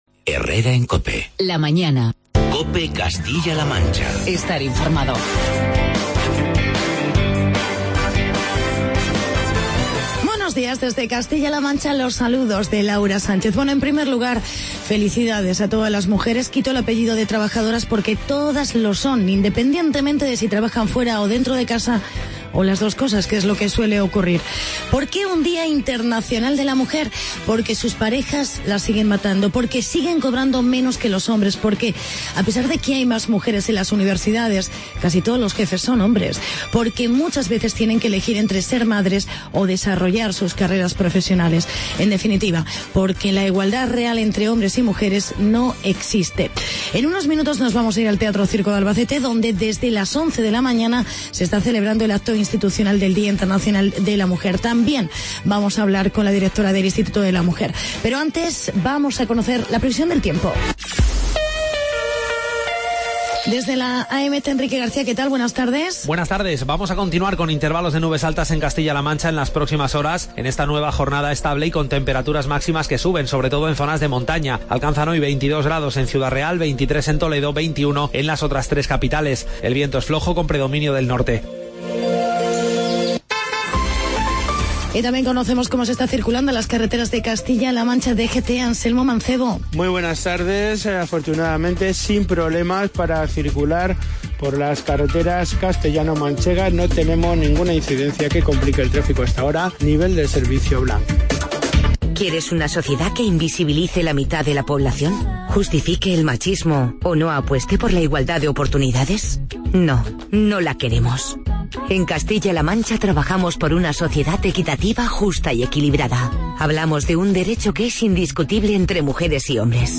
Actualidad y entrevista con la Directora del Instituto de la Mujer, Araceli Martínez.